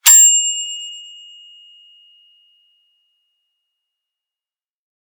typewrite bell1.mp3